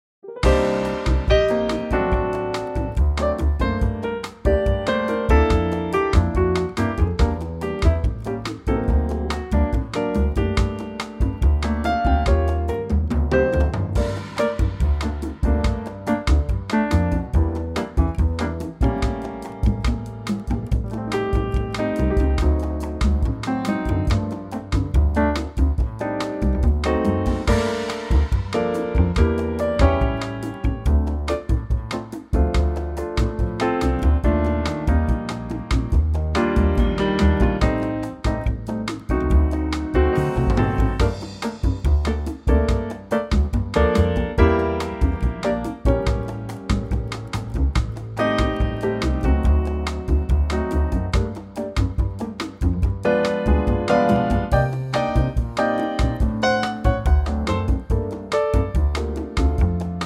latin gem
in a trio plus percussion arrangement.